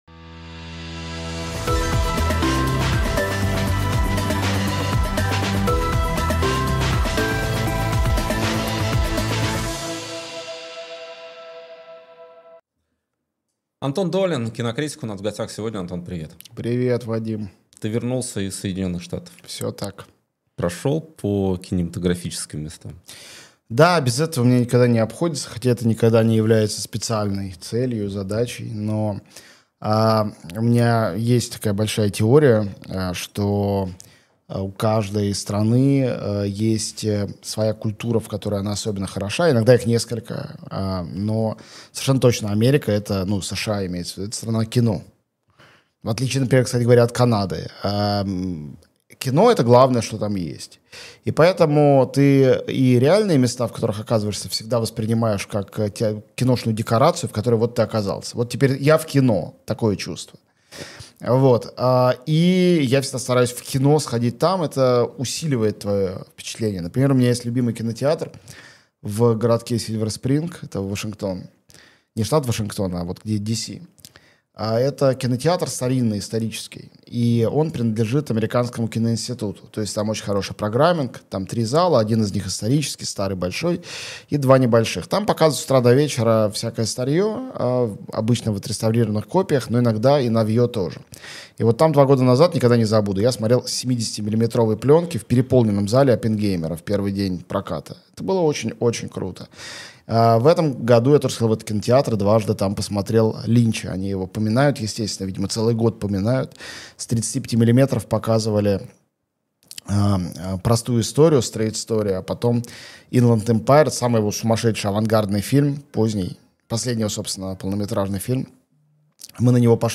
Антон Долин кинокритик